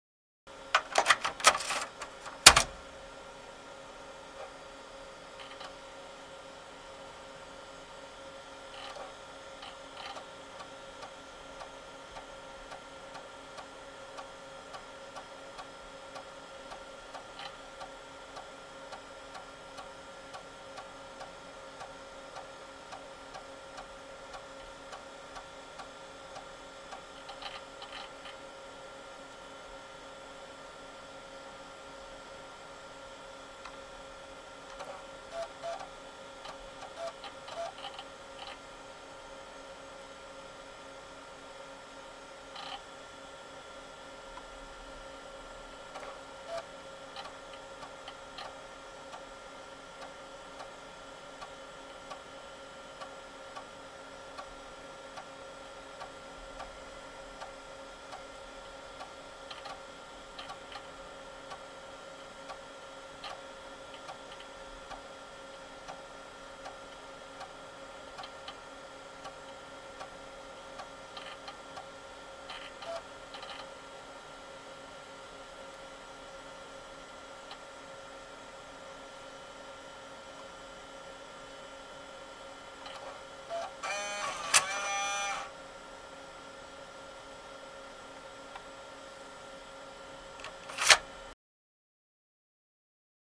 Mac Floppy Drive | Sneak On The Lot
Mac Floppy Disk Drive; Read / Write; Desktop Computer Floppy Disk Drive; Computer Fan Idle / Floppy Insert / Read / Write / Eject, Close Perspective.